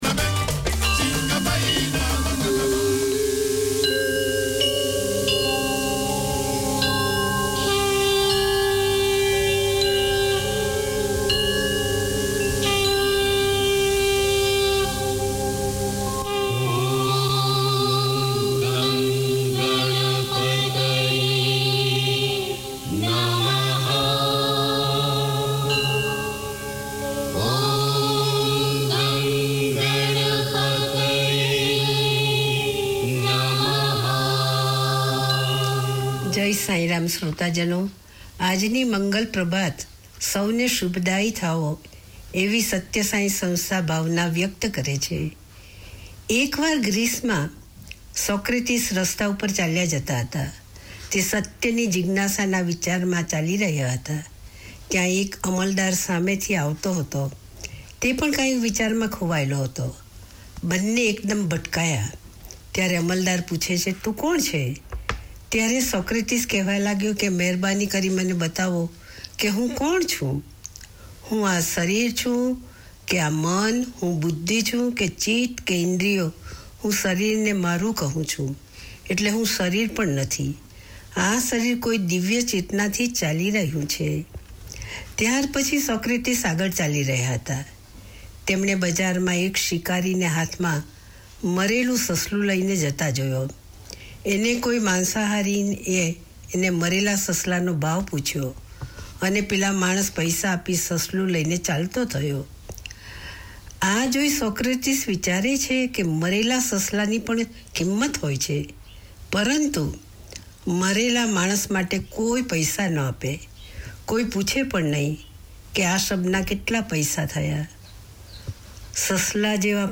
Each week Sai Baba devotees can pause for ten minutes to consider the teachings of Satya Sai Baba and hear devotional songs.